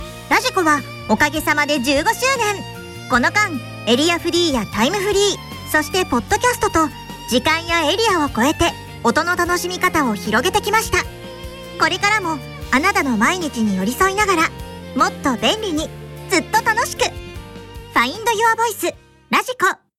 radiko15周年CM 20秒/40秒